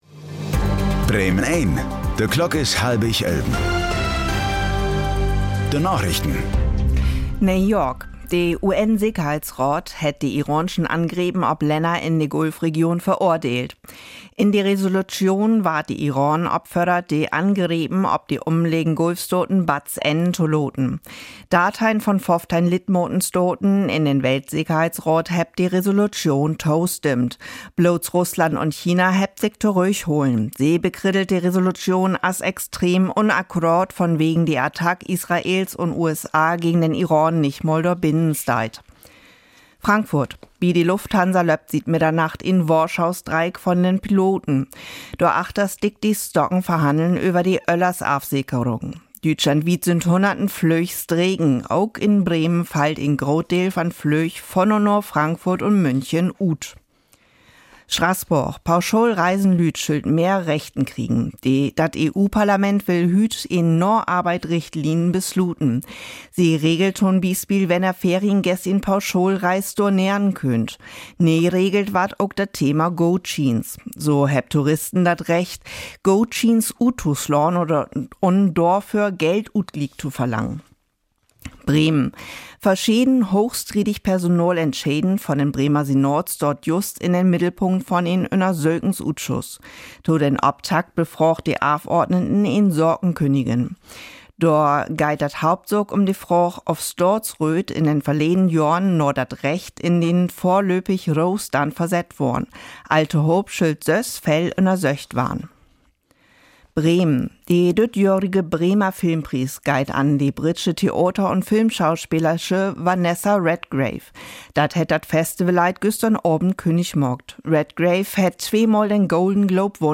Plattdüütsche Narichten vun'n 12. März 2026